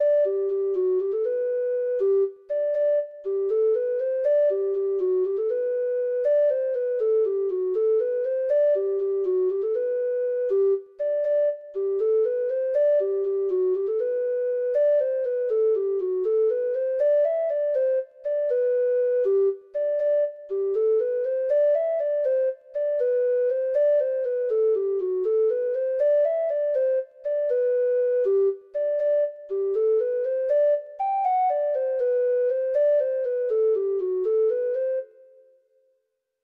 Traditional Trad. Tea in the Morning (Irish Folk Song) (Ireland) Treble Clef Instrument version
Traditional Music of unknown author.
Irish Slip Jigs